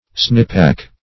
snippack - definition of snippack - synonyms, pronunciation, spelling from Free Dictionary Search Result for " snippack" : The Collaborative International Dictionary of English v.0.48: Snippack \Snip"pack\, n. [Cf. Snipe .]